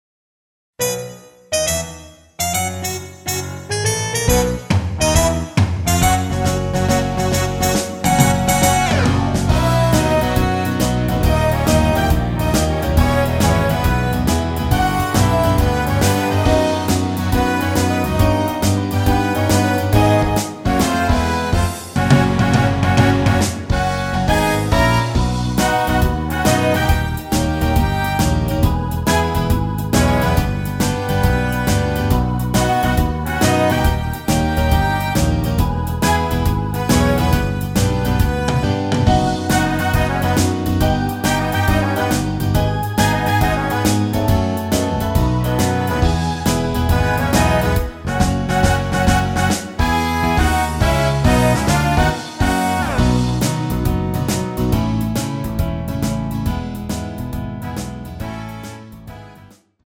앞부분30초, 뒷부분30초씩 편집해서 올려 드리고 있습니다.
곡명 옆 (-1)은 반음 내림, (+1)은 반음 올림 입니다.